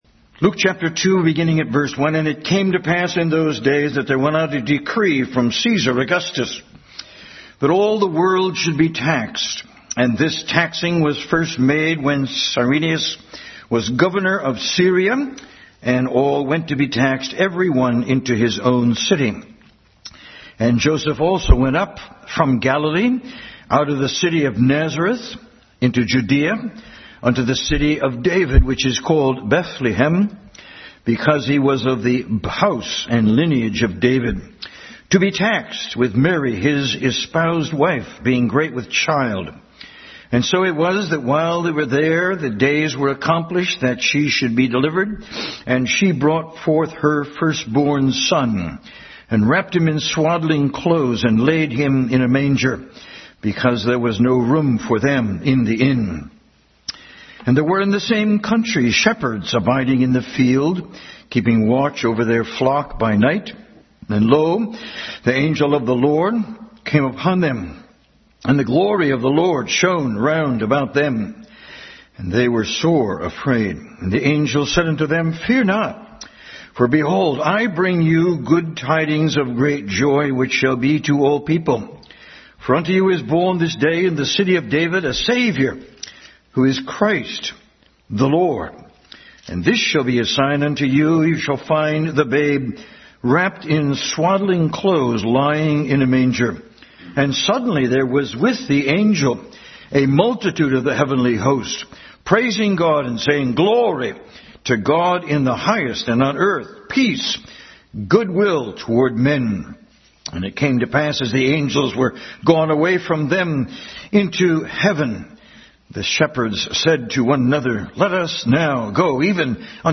Luke 2 Service Type: Family Bible Hour Bible Text